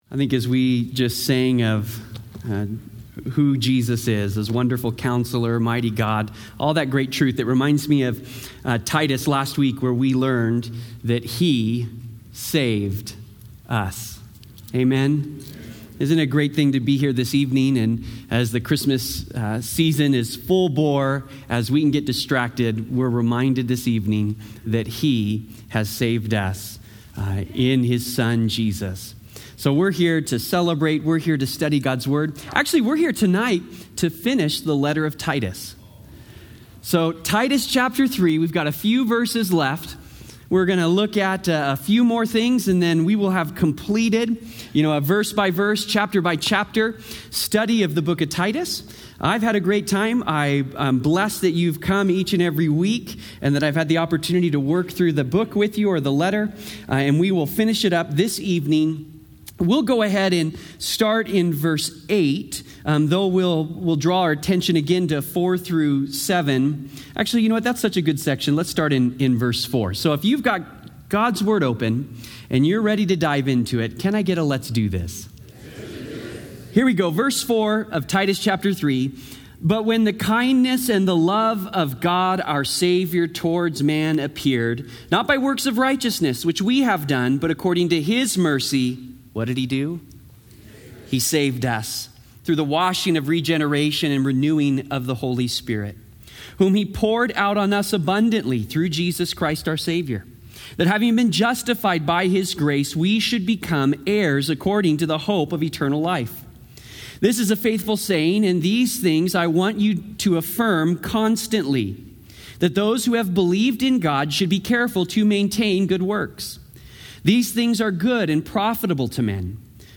Sermon Details Calvary Chapel High Desert